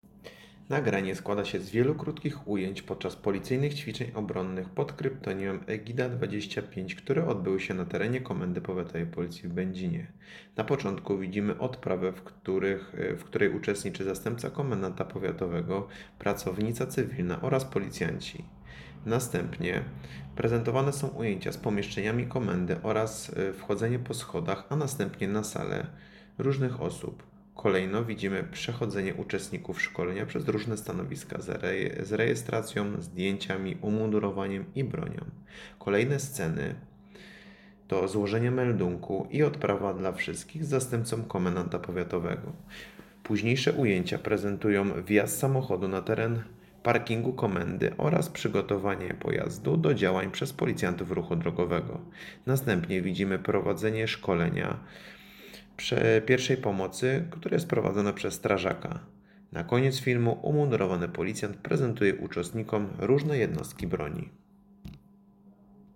Nagranie audio Egida_Audiodeskrypcja.mp3